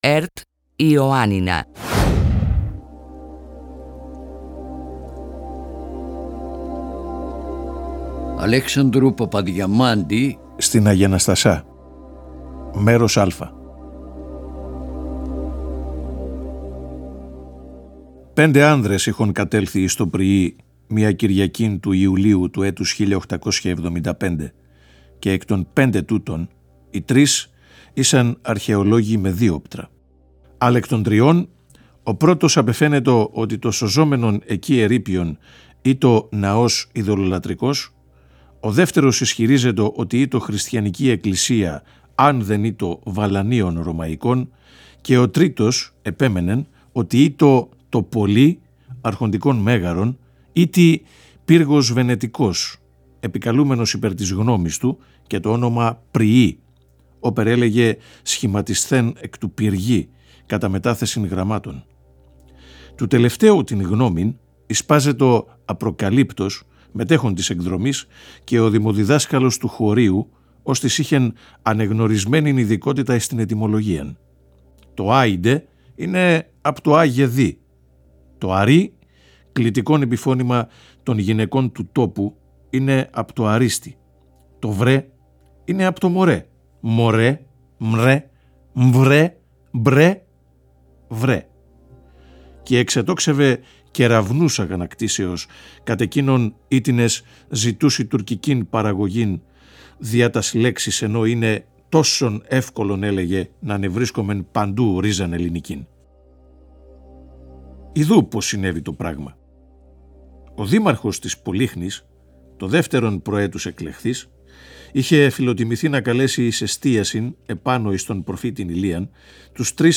αφηγείται το πρώτο μέρος από το διήγημα του Αλέξανδρου Παπαδιαμάντη «Στην Αγιαναστασιά». Στο διήγημα αυτό, ο ήρωας περιγράφει την επίσκεψή του στο εγκαταλελειμμένο εκκλησάκι της Αγίας Αναστασίας της Φαρμακολυτρίας, όπου θα συναντήσει και την ξαδέρφη του Μαχούλα, που είχε να τη δει είκοσι χρόνια. Μια αναδρομή στο παρελθόν φανερώνει μια παράξενη λειτουργία που είχε τελέσει η ξαδέρφη του σ’ εκείνο το εκκλησάκι ελπίζοντας να γλιτώσει ο γιος της από τα «μάγια» του έρωτα.